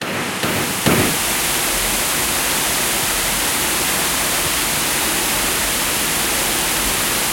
fireAlarmExtinguishersLoop.ogg